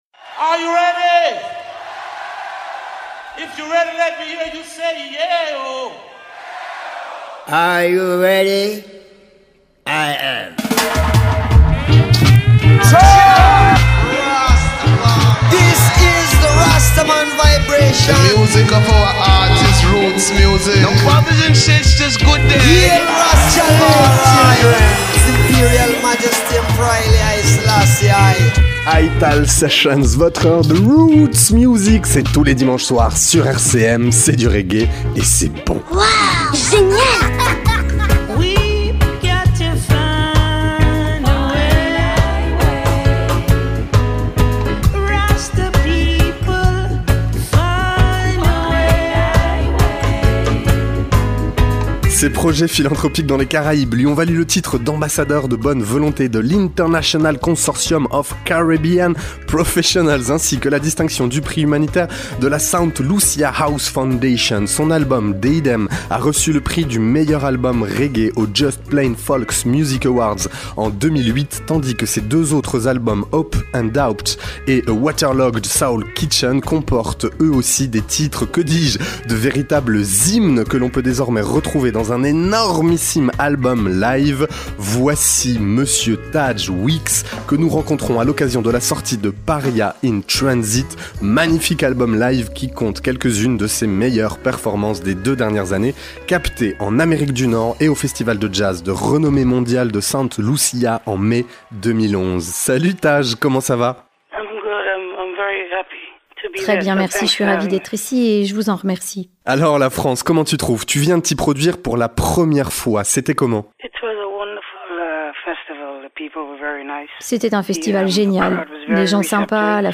Interview
Interview jusqu'à 11'28 puis le titre "Jordan".